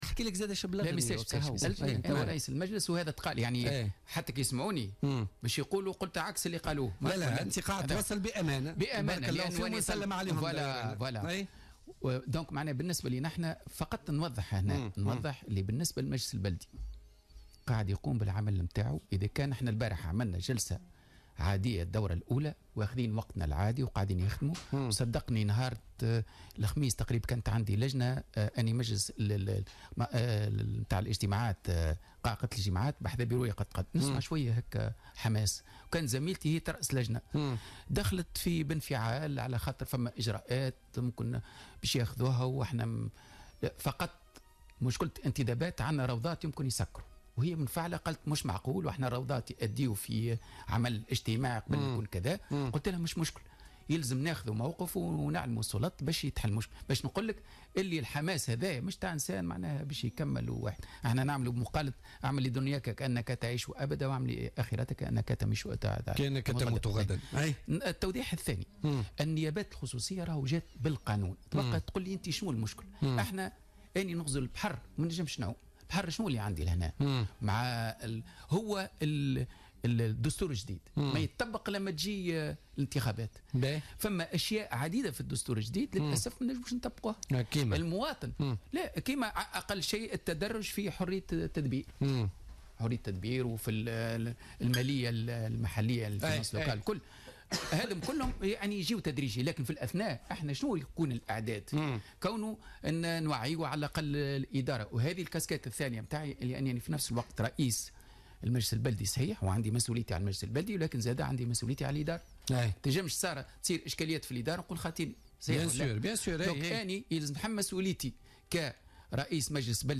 أكد رئيس بلدية سوسة محمد المكني ضيف بولتيكا اليوم الإثنين 13 مارس 2017 الوضع البلدي وصل الى ما وصل اليه بسبب تراكمات 4 سنوات مضت.